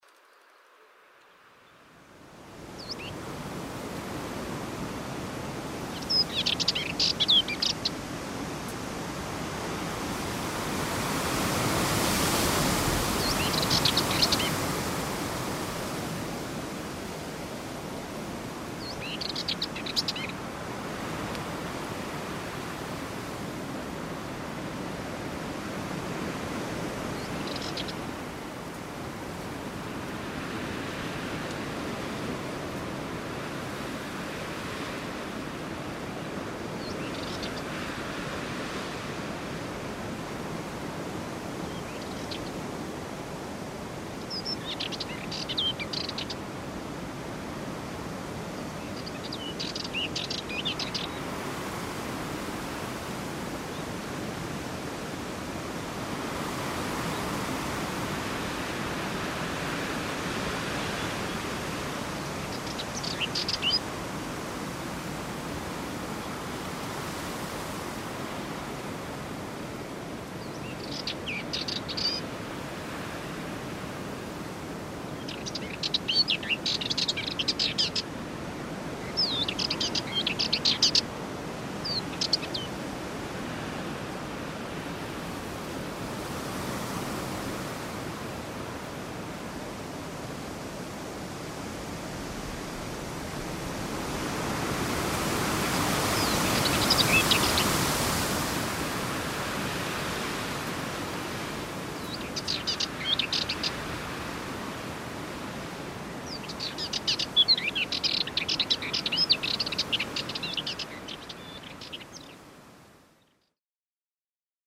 C’était le cas en bord de mer au nord de la Sardaigne alors qu’une Fauvette sarde semblait chanter “contre le vent”, ou dans les grottes à ciel ouvert de Tiscali dans lesquelles le vent jouait avec l’acoustique d’une cathédrale.
LA FAUVETTE SARDE DANS LE VENT
fauvette-vent.mp3